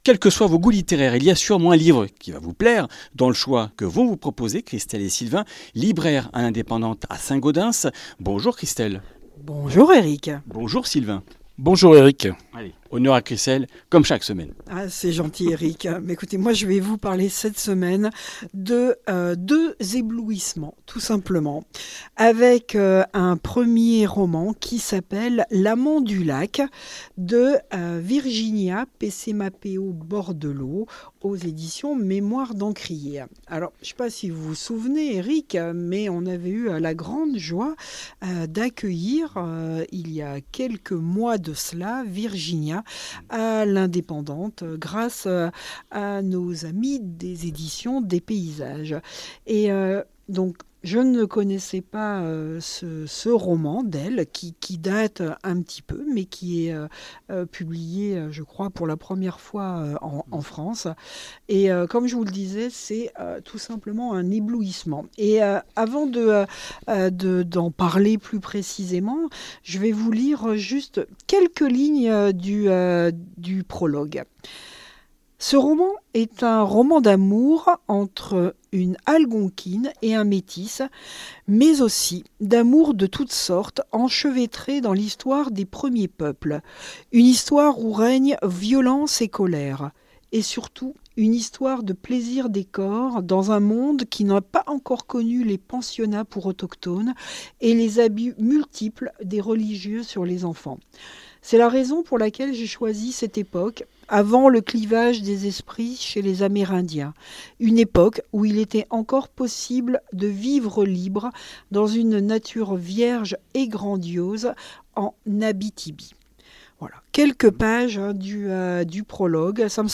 Comminges Interviews du 14 mars